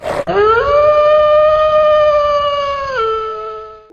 howl.ogg